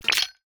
UIClick_Bubbles Splash Chains Metal 02.wav